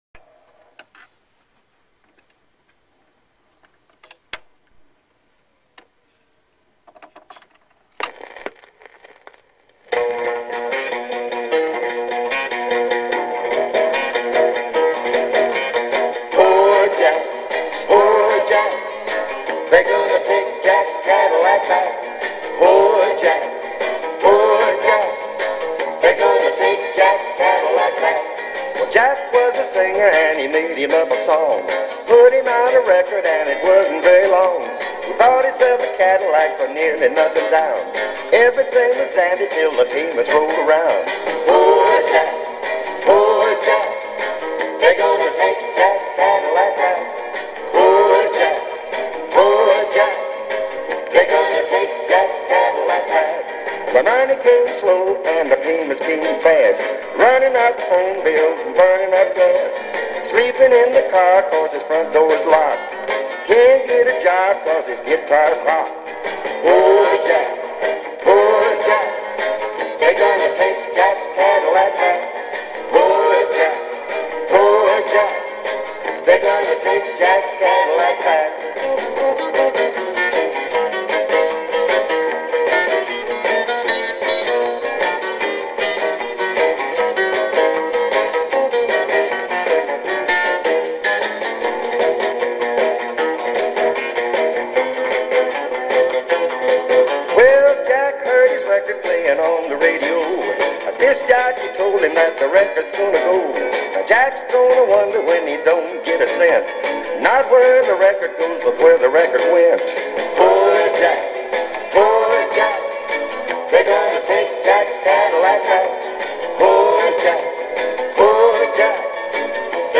RARE 45, in very good shape.